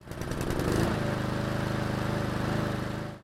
描述：车辆发动机点火
Tag: 汽车发动机ignittion 汽车发动机启动 汽车